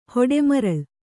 ♪ hoḍe maraḷ